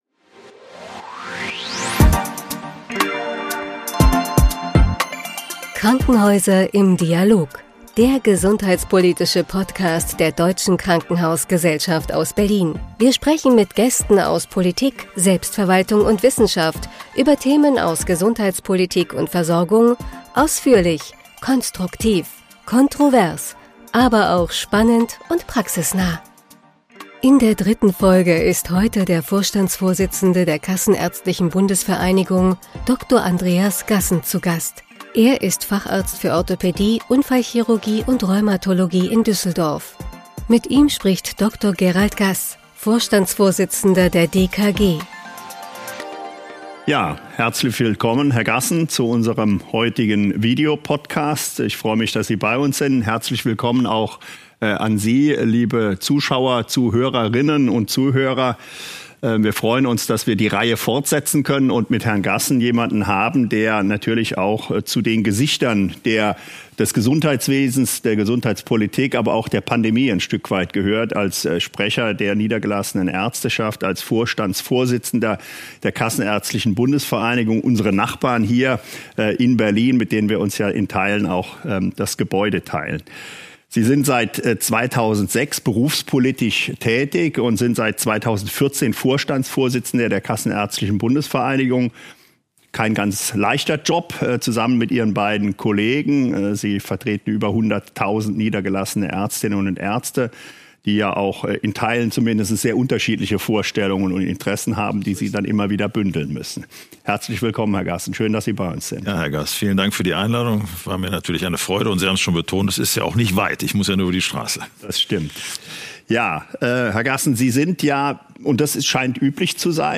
In der 3. Folge ist Dr. Andreas Gassen, Vorstandsvorsitzender der Kassenärztlichen Bundesvereinigung (KBV), zu Gast.
Mit Dr. Gerald Gaß, Vorstandsvorsitzender der DKG, spricht er über die Herausforderungen der Corona-Pandemie, die mediale Berichterstattung und die Besetzung des Expertenbeirats ohne Praxisvertreter. Weitere Themen sind Eigenverantwortlichkeit in Zusammenhang mit Impfpflicht und Impfquote, die ambulante Notfallversorgung und die sektorenübergreifende Versorgung.